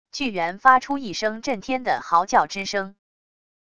巨猿发出一声震天的嚎叫之声wav音频